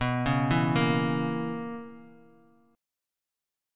BM7sus2sus4 Chord